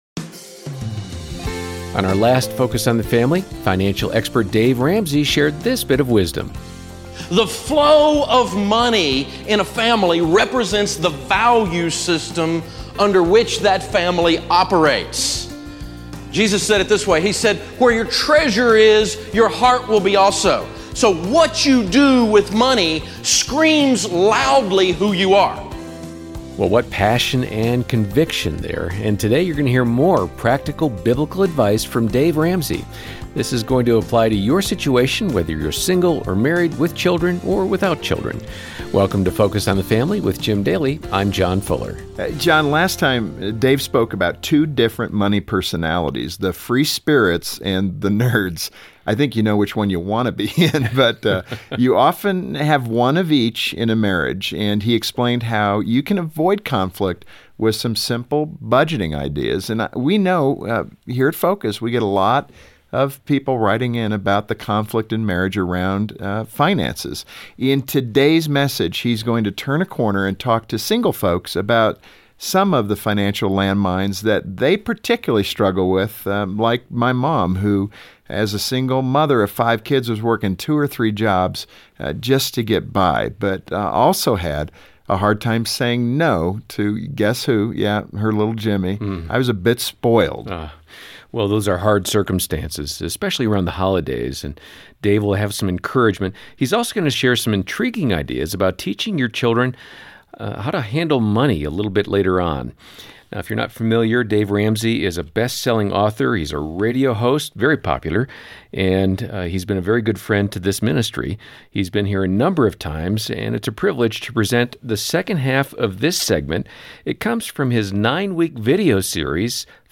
In this upbeat workshop, Dave Ramsey reminds couples that if they can agree on their spending, they will be more united in pursuing their goals and dreams together. He reminds parents of the importance of educating their children in the arts of spending, saving, and giving, and offers encouragement to singles as well.